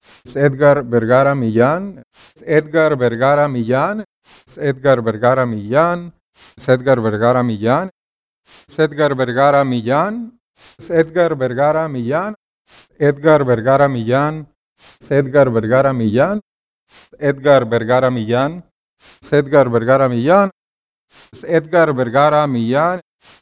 As a native Spanish speaker, his pronounciation may sound strange (and unusually fast) to a native English speaker.